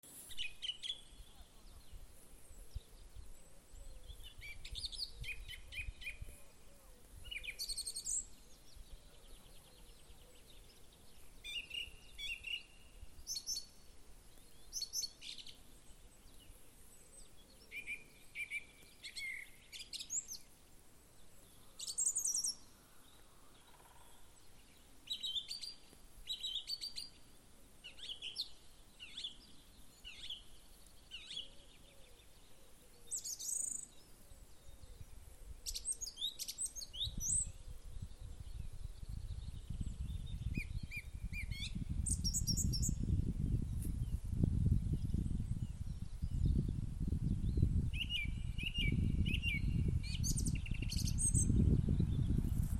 Птицы -> Дроздовые -> 1
певчий дрозд, Turdus philomelos
СтатусПоёт